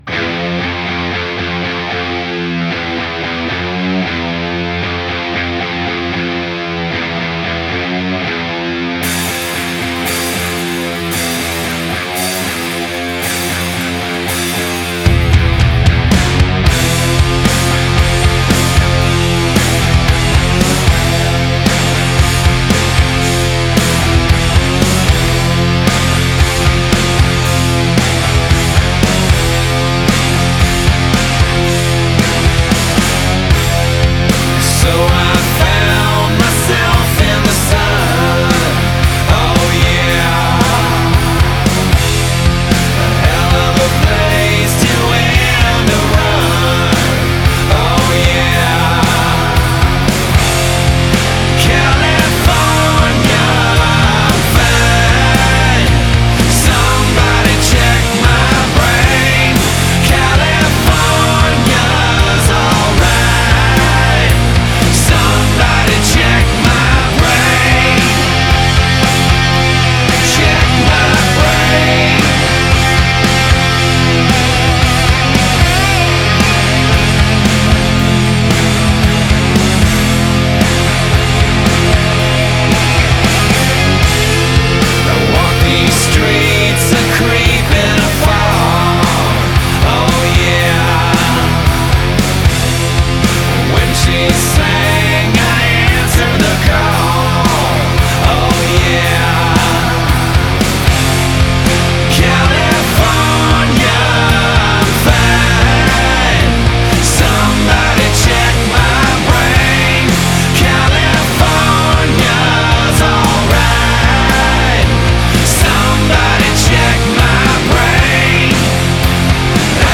Назад в alternative music